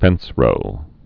(fĕnsrō)